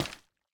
resin_step2.ogg